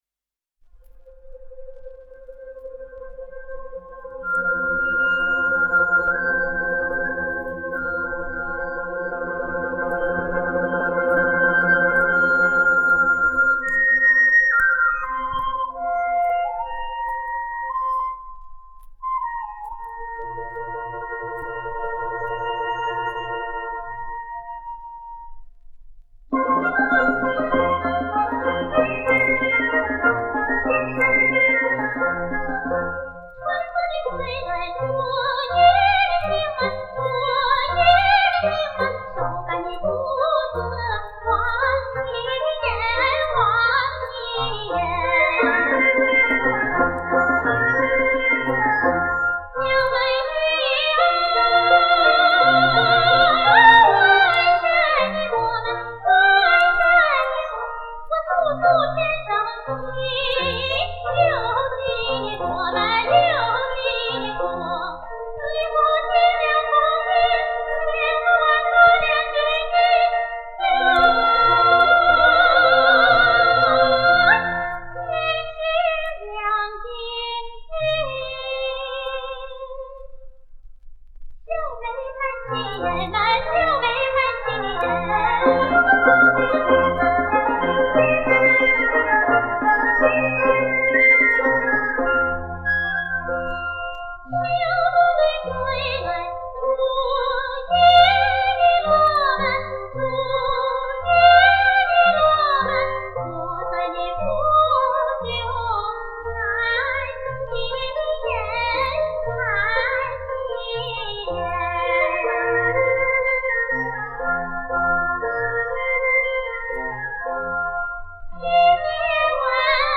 云南滇西民歌